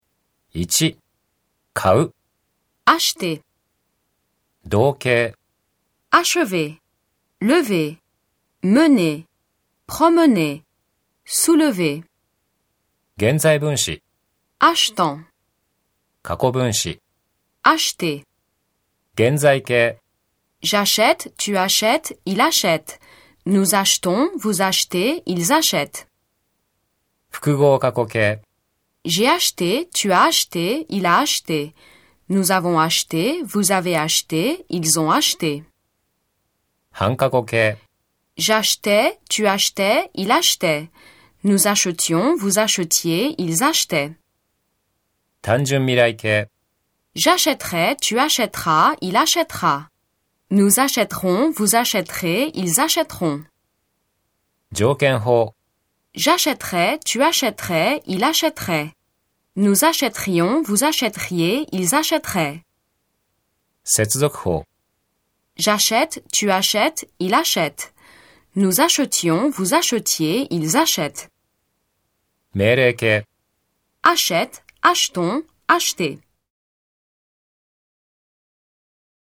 リズミカルでしょ？